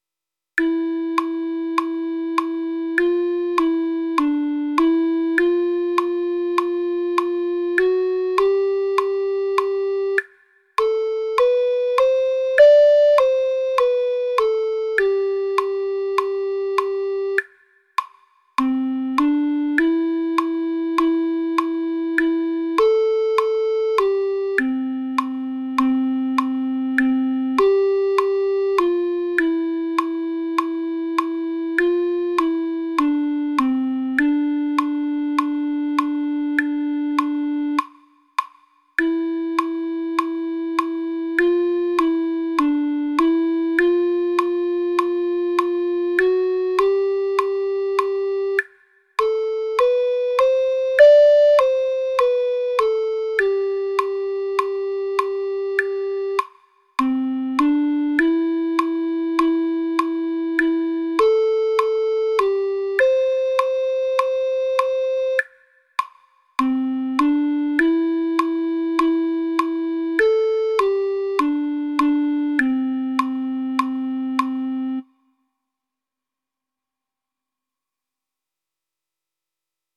Flauta dulce y guitarra.